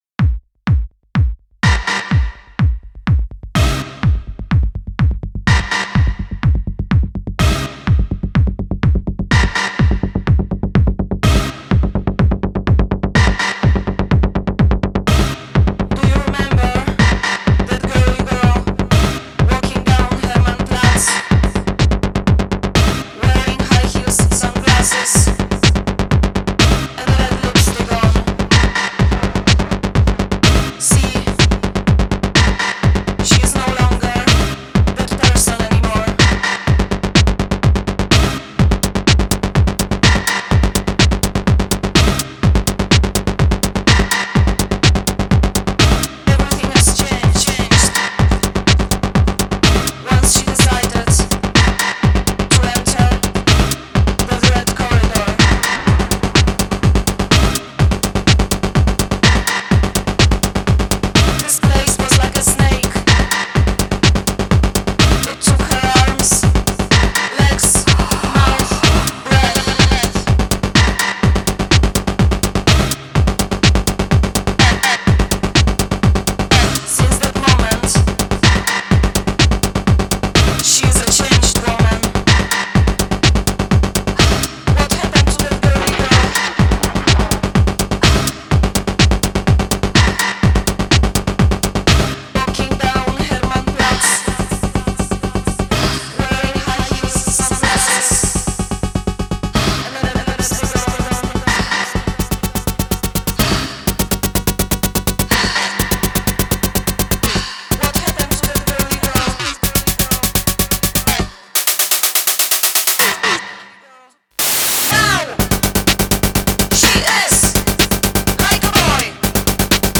Cette gonz en live elle nique tout.